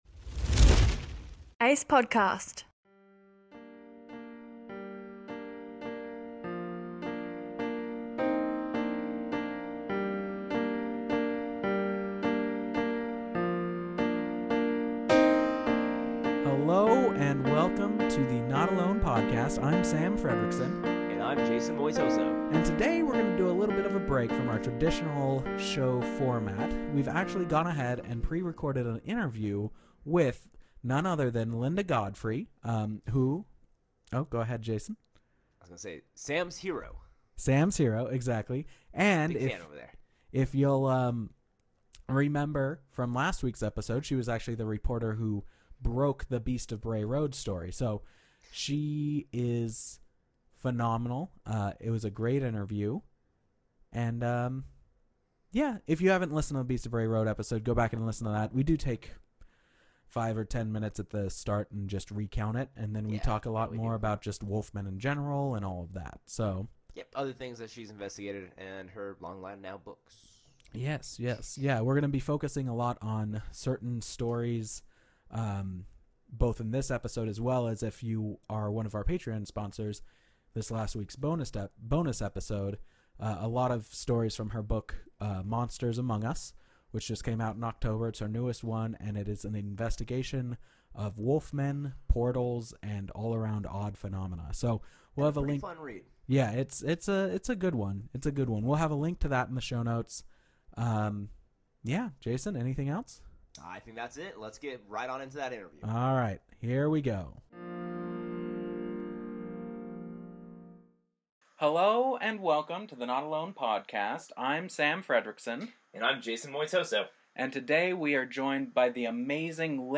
professional interview